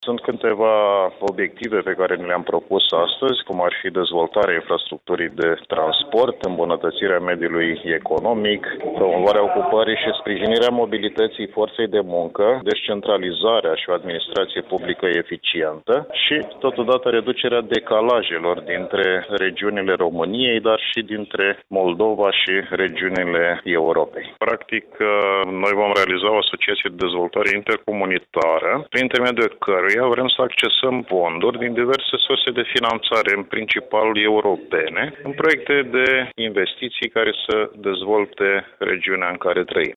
Primarul municipiului Piatra Neamţ, Dragoş Chitic, a declarat pentru postul nostru de radio, că scopul unei astfel de asociaţii este acela de a promova proiecte comune, finanţate în principal din fonduri europene, în vederea dezvoltării regiunii Moldova.